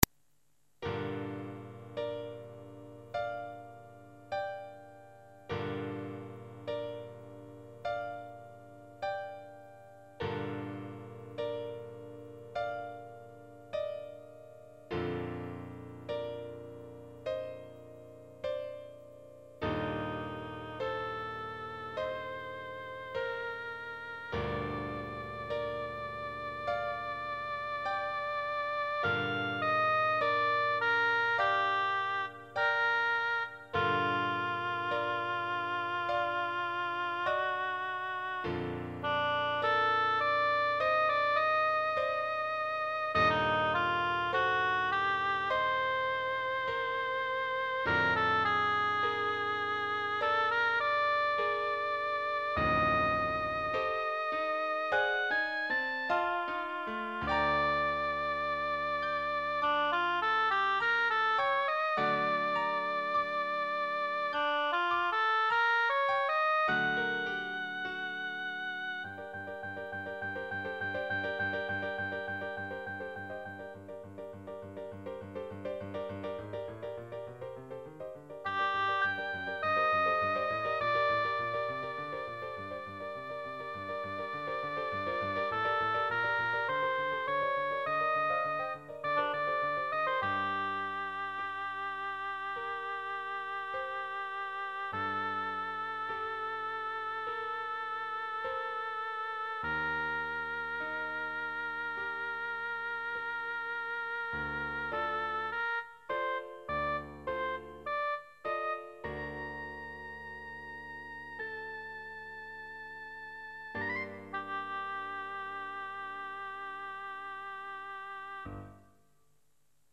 Note : au début de chaque fichier sonore crée à partir d'une maquette, un petit bruit très désagréable se fait entendre.
*Les pièces de musique de chambre
Pour ceux que ça intéresse, les maquettes ont été réalisées avec un clavier électronique Technics sx-kn3000